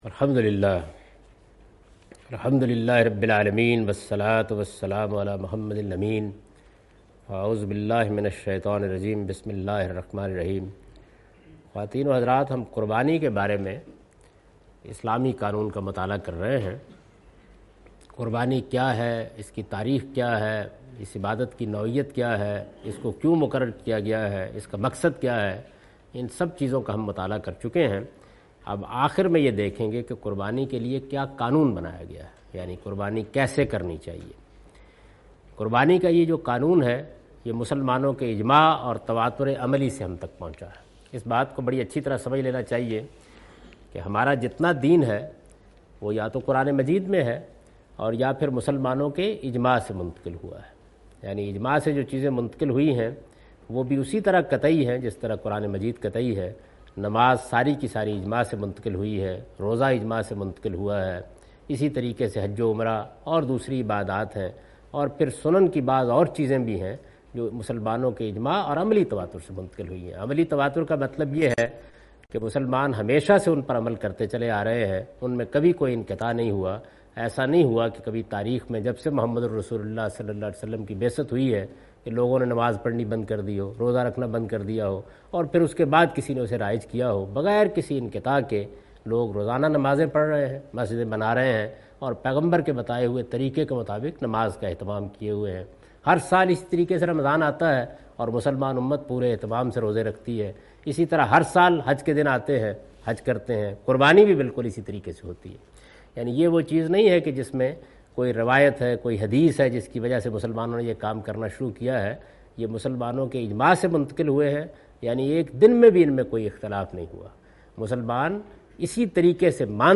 Philosophy, history, objectives and sharia (law) of Qurbani taught by Javed Ahmad Ghamidi from his book Meezan under Al Islam Course organized by Al Mawrid.